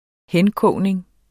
Udtale [ -ˌkɔwˀneŋ ]